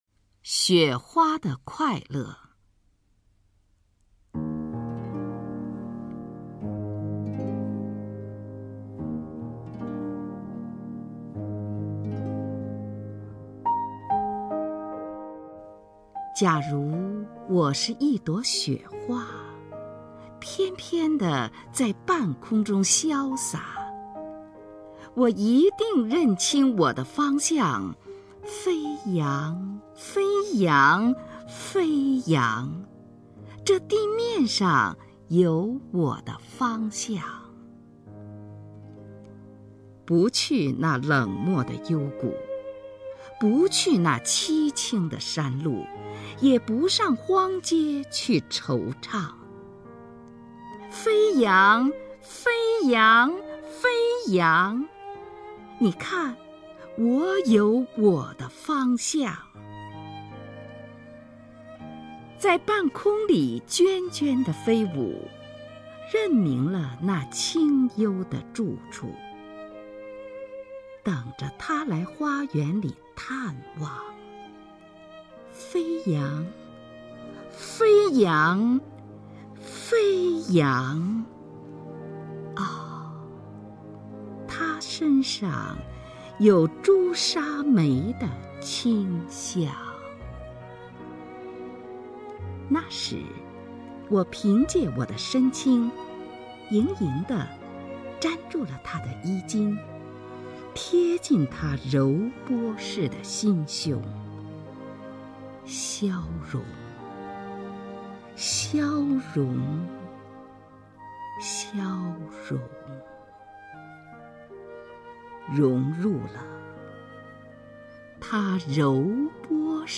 虹云朗诵：《雪花的快乐》(徐志摩)　/ 徐志摩
名家朗诵欣赏 虹云 目录
XueHuaDeKuaiLe_XuZhiMo(HongYun).mp3